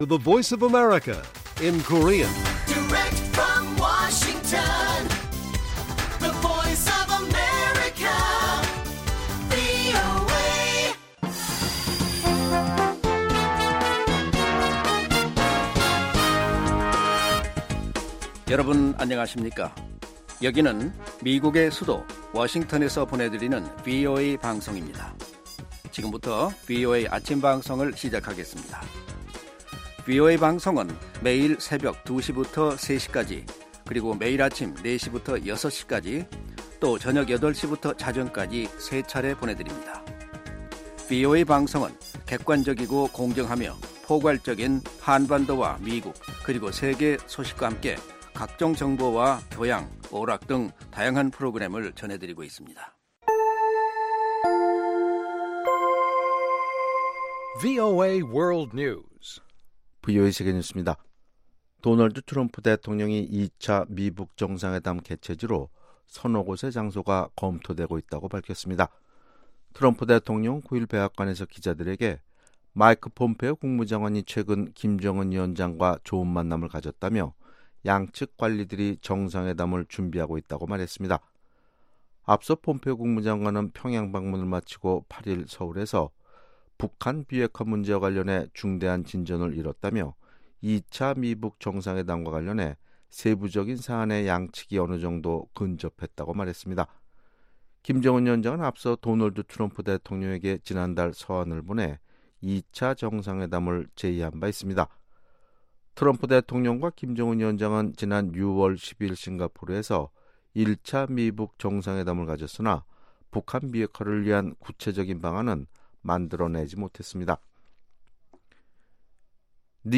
세계 뉴스와 함께 미국의 모든 것을 소개하는 '생방송 여기는 워싱턴입니다', 2018년 10월 10일 아침 방송입니다. ‘지구촌 오늘’에서는 사우디아라비아 반체제 언론인이 터키에서 실종돼 파장이 커지고 있는 가운데 미국 정부가 사우디 측에 철저한 수사를 요구했다는 소식, ‘아메리카 나우’에서는 브렛 캐버노 신임 연방 대법관이 백악관에서 선서식을 했다는 이야기를 소개합니다.